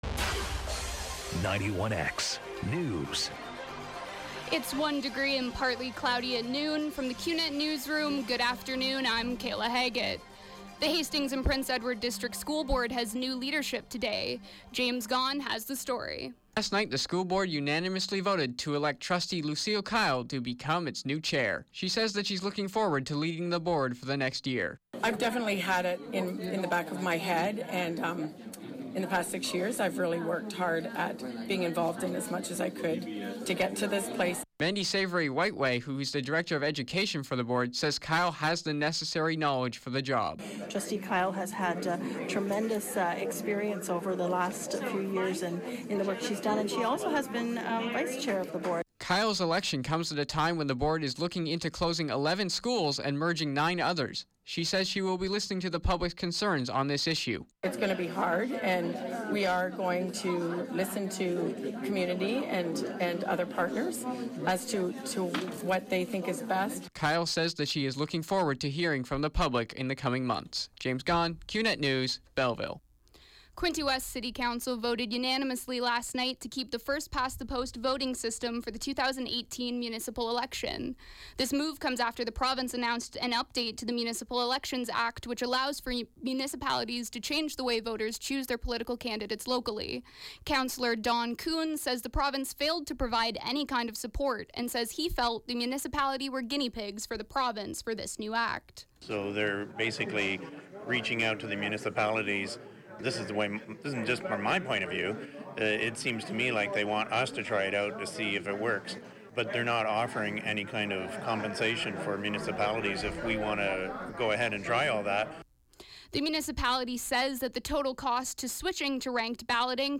91X Newscast – Tuesday, Dec. 6, 2016, 12 p.m.